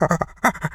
monkey_chatter_03.wav